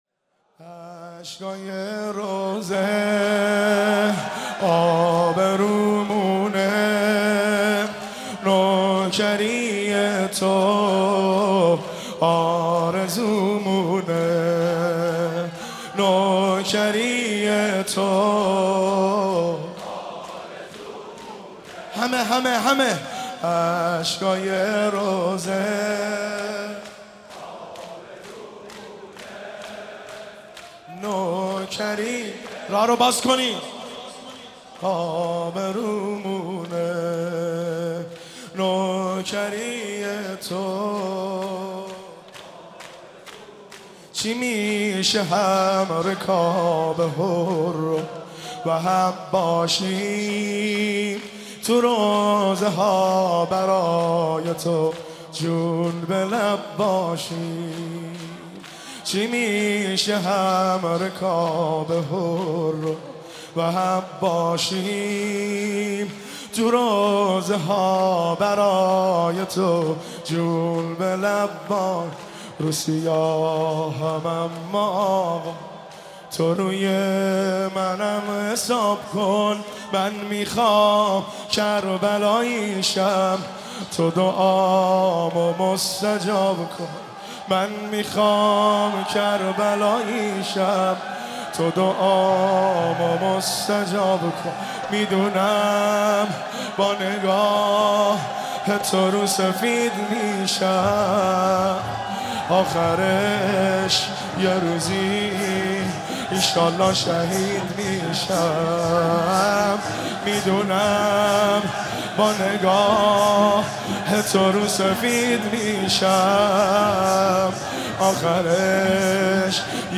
مداحی اشهکای روضه، آبرومونه(شور)
شب ششم محرم 1393
هیئت خادم الرضا(ع) قم